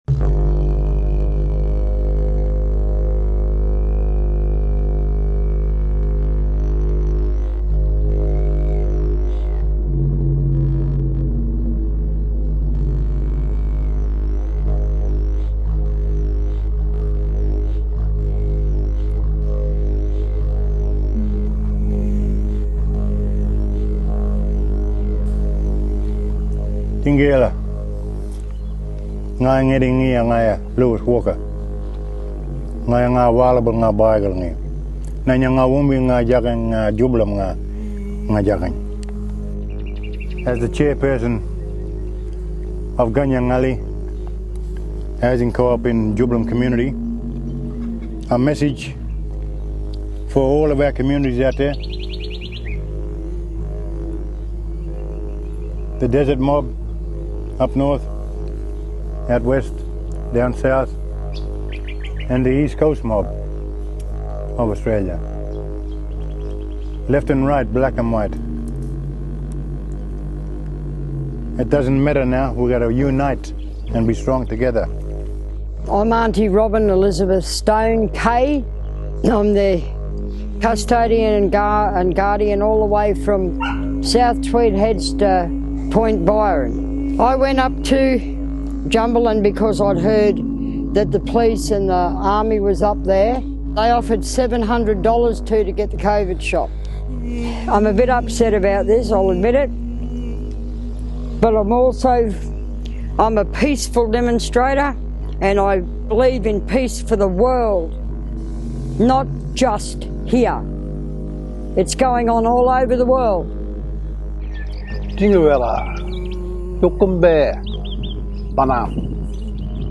Australian Elders Jubullum Message to All Peoples: